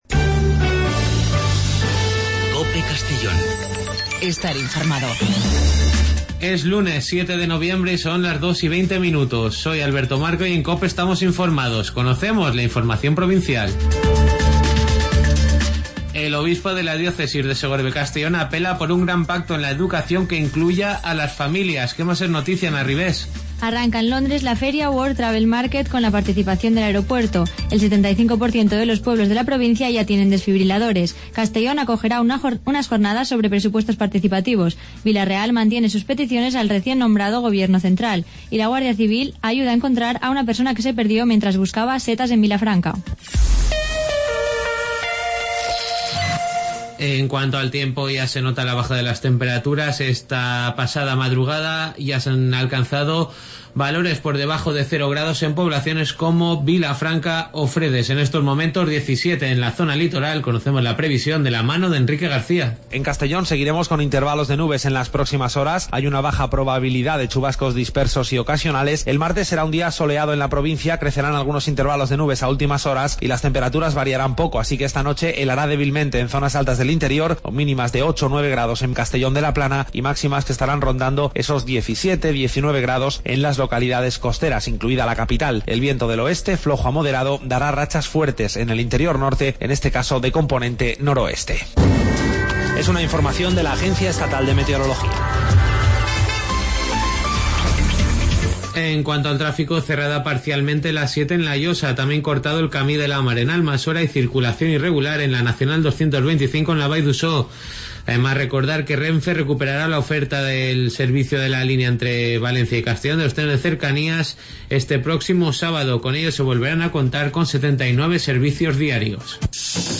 Redacción digital Madrid - Publicado el 07 nov 2016, 15:10 - Actualizado 19 mar 2023, 03:05 1 min lectura Descargar Facebook Twitter Whatsapp Telegram Enviar por email Copiar enlace Las noticias del día de 14:20 a 14:30 en Informativo Mediodía COPE en Castellón.